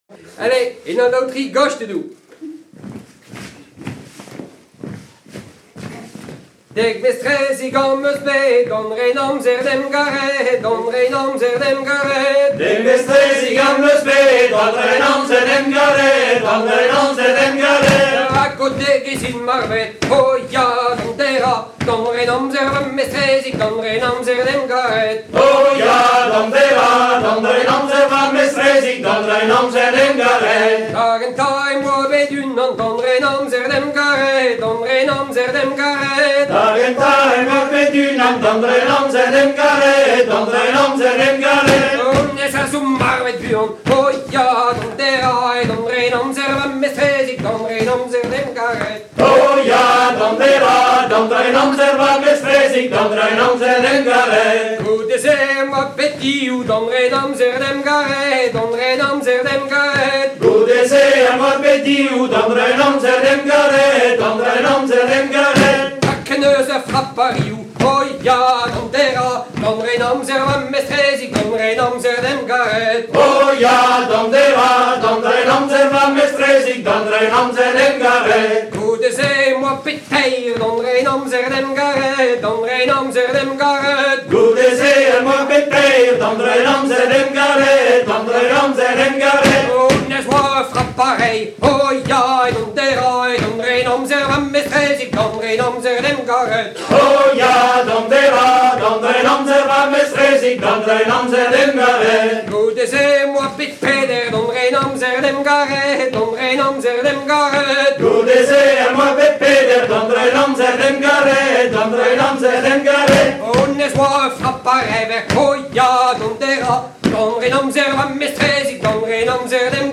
Cornemuse / Bombarde - Batterie - Notice - MP3
Ronds Pagan: PDF Cornemuse Bombarde Batterie | MP3 Ar Mezvier |